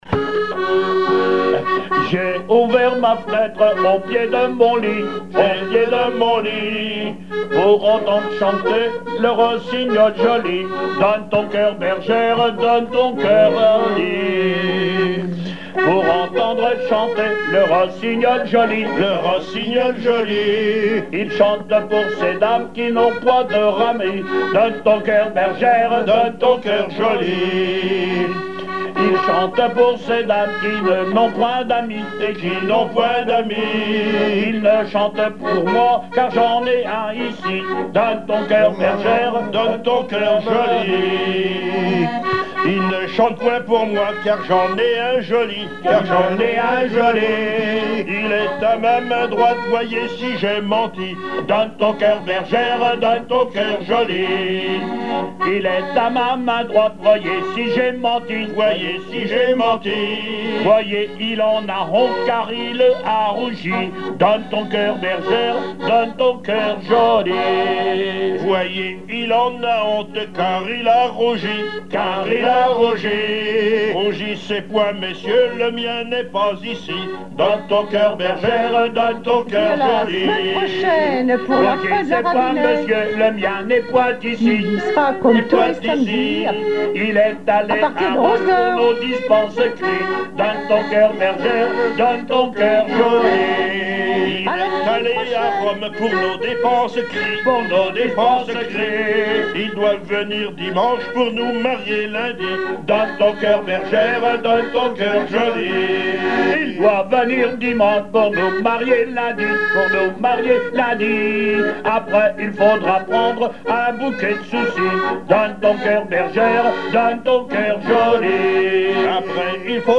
Chansons en dansant
émission La fin de la Rabinaïe sur Alouette
Pièce musicale inédite